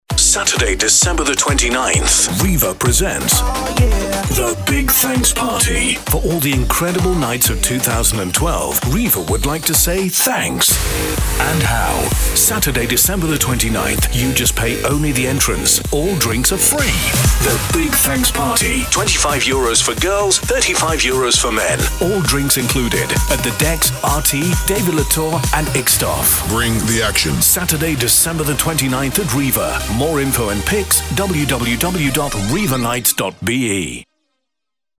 Man